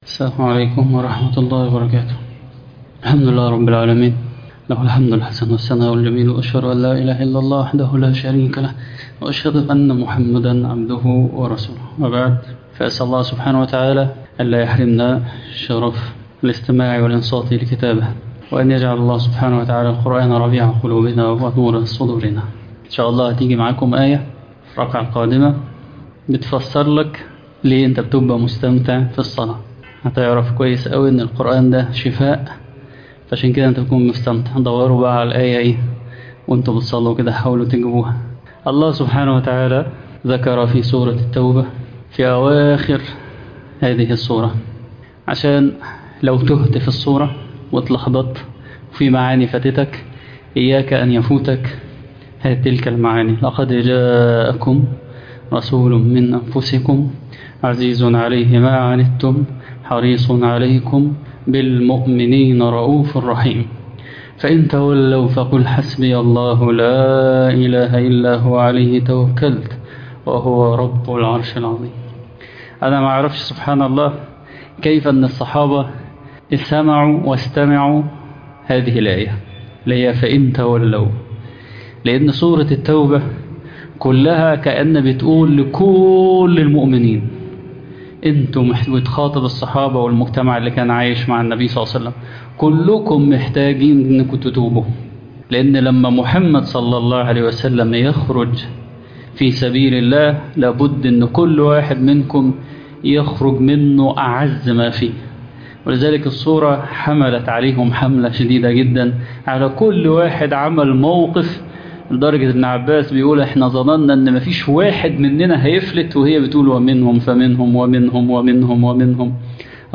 تفاصيل المادة عنوان المادة درس التراويح ليلة 11 رمضان تاريخ التحميل الثلاثاء 21 ابريل 2026 مـ حجم المادة 5.79 ميجا بايت عدد الزيارات 4 زيارة عدد مرات الحفظ 1 مرة إستماع المادة حفظ المادة اضف تعليقك أرسل لصديق